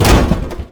2 BROKEN - CK - BEAM Wood Ceiling Drop On Concrete.wav